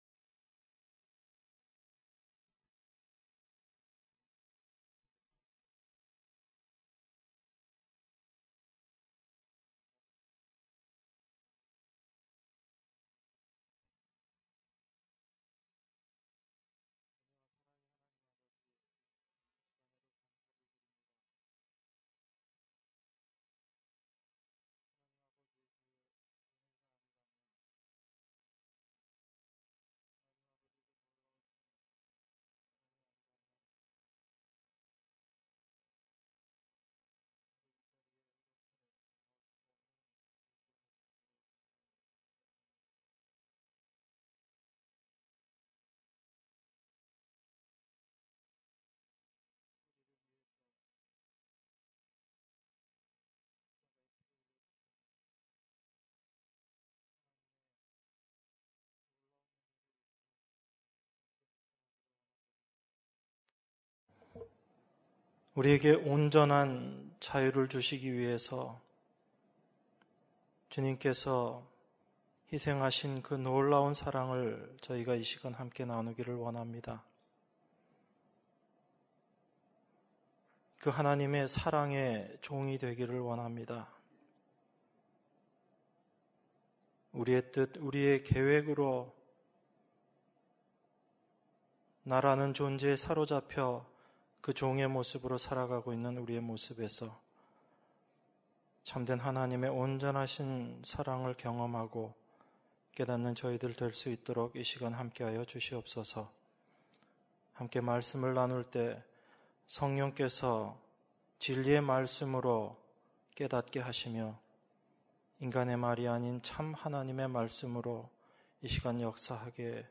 수요예배 - 갈라디아서 5장 1절-6절